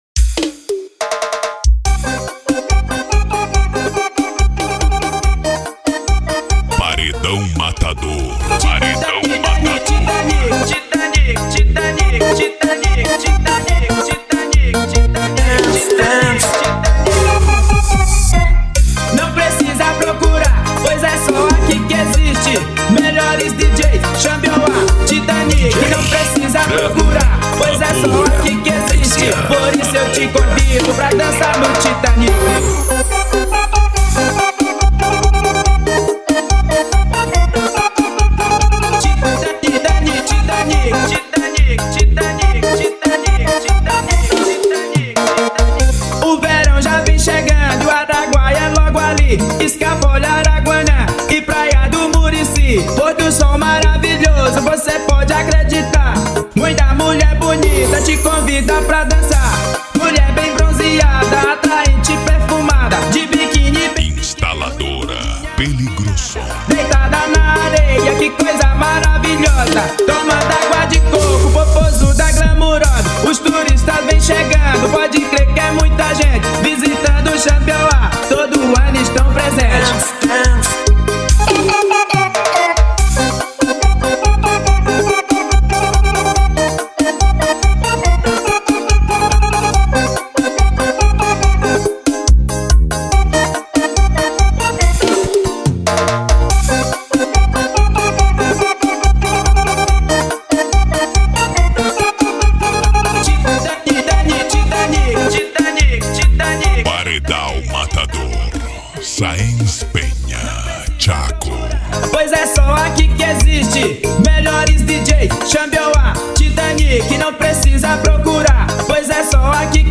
Bass
Mega Funk
Psy Trance